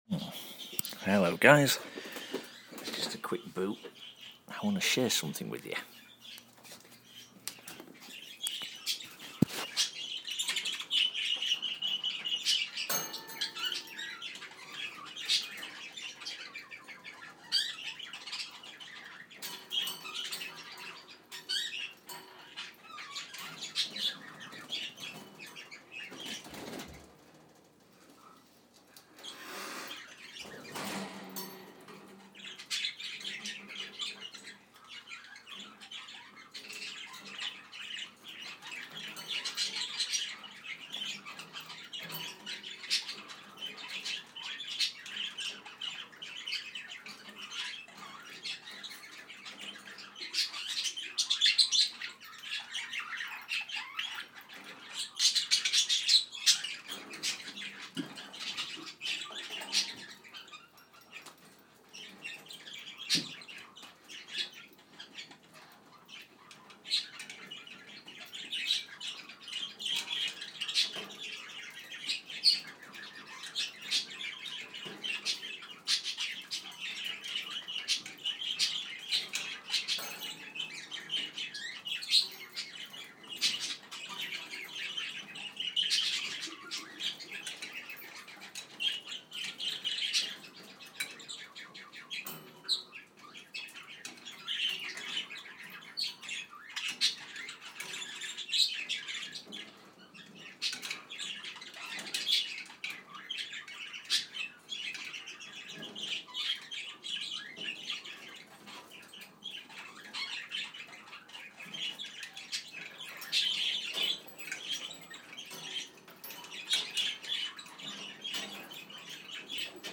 Happily chatting budgies.